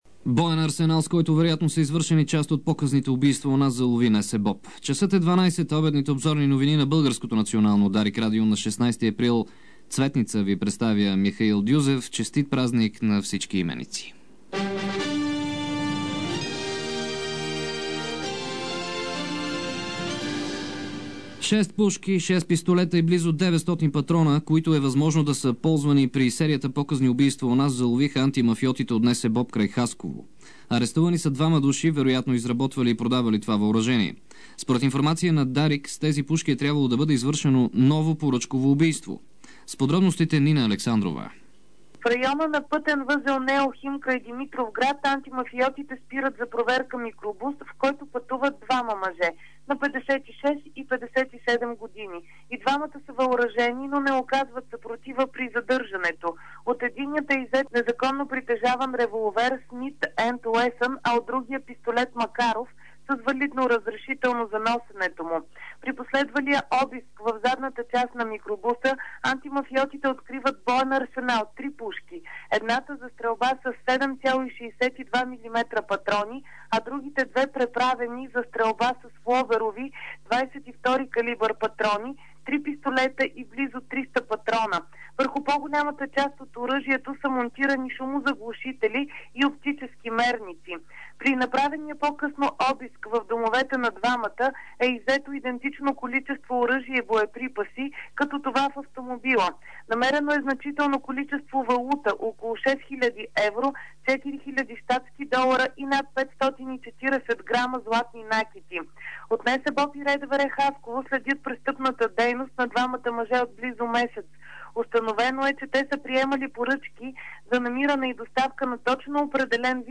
DarikNews audio: Обедна информационна емисия – 16.04.2006